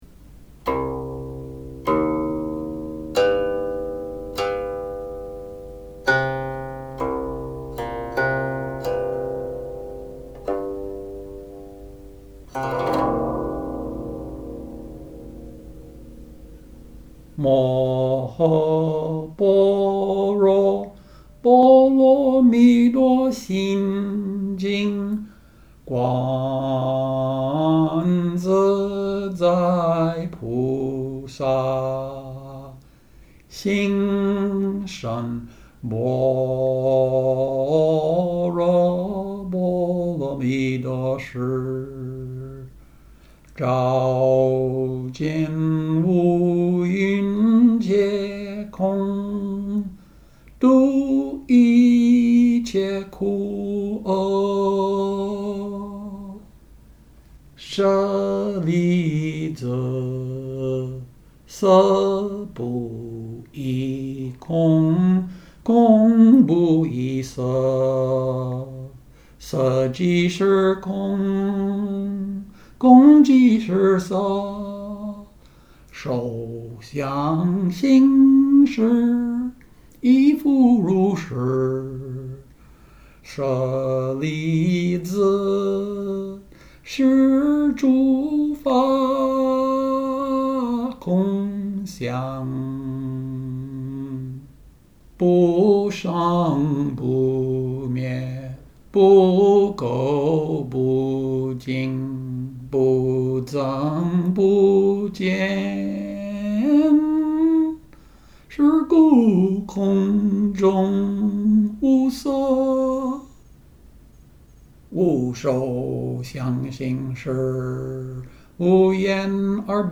Music and Lyrics: Seven sections plus Coda 16 Words and music are paired by formula (complete pdf)
This 1625 setting is largely syllabic, following the traditional pairing method for Chinese melodies with lyrics.
voice solo (05.37)         video of qin solo (04.20)